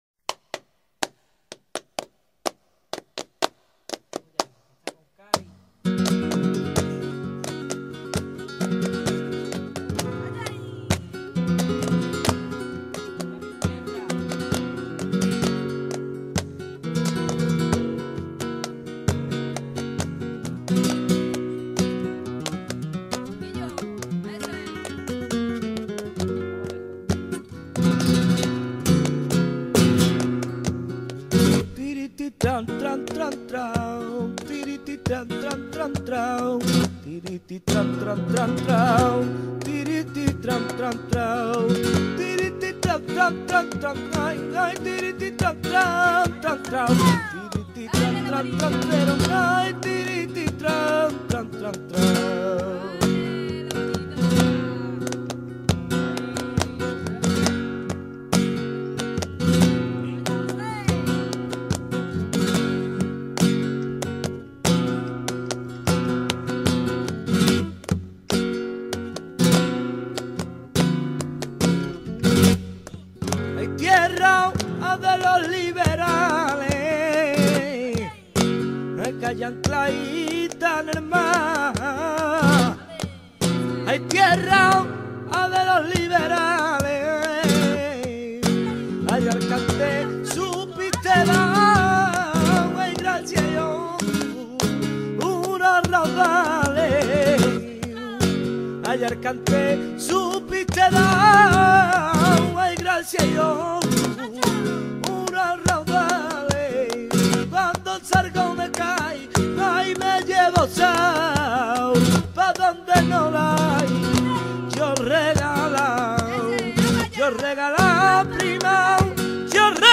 Flamenco Alegrías
alegrias-de-cadiz.mp3